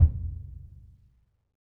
BDrumNewhit_v2_rr2_Sum.wav